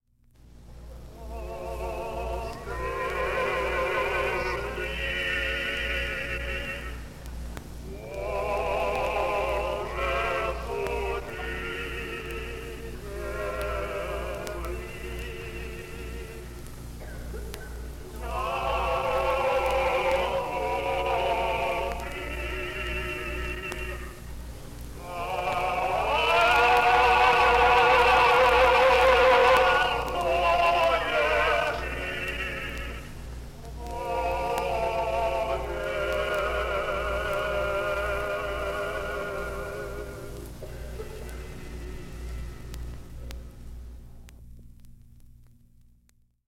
Служение Патриарха Алексия I-го. Великий Пост и Пасха 1962г.